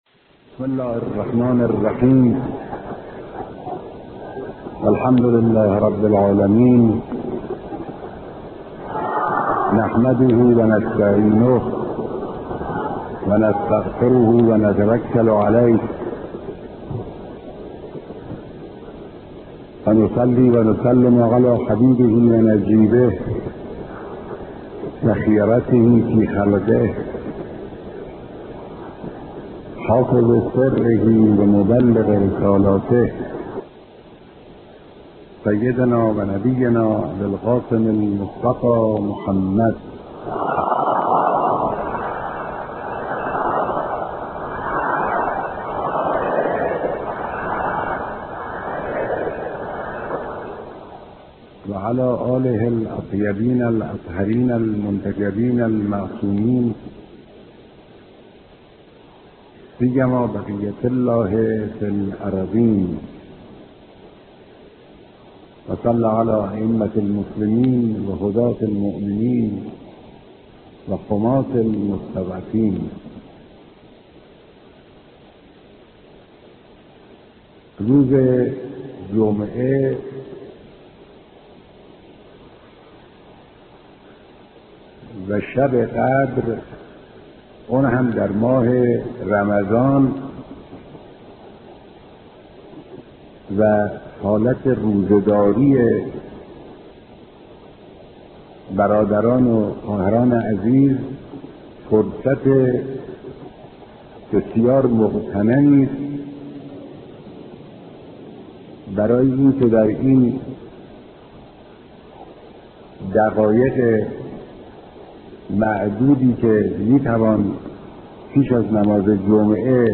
خطبه‌های نماز جمعه‌ی تهران، رمضان 1429